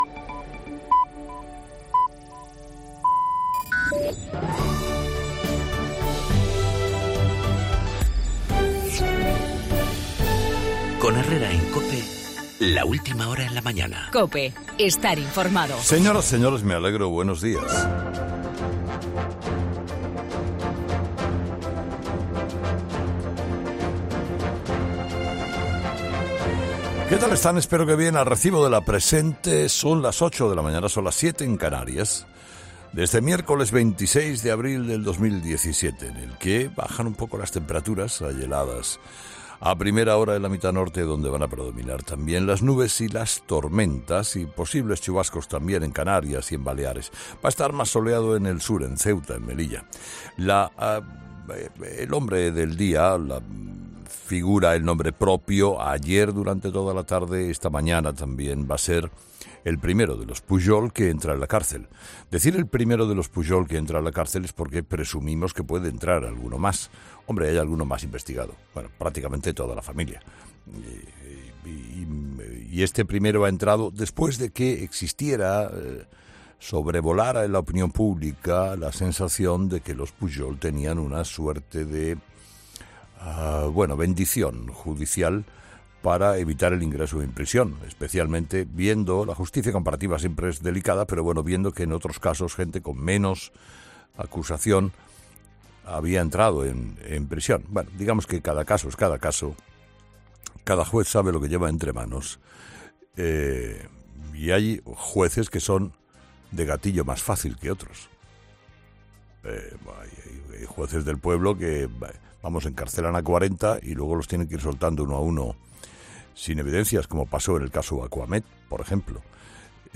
La entrada en prisión del hijo de Jordi Pujol, la operación Lezo, las amenazas de Lluis Llach y un tuit de un colectivo estudiantil de Zaragoza sobre San Jorge, en el monólogo de Carlos Herrera a las 8 de la mañana.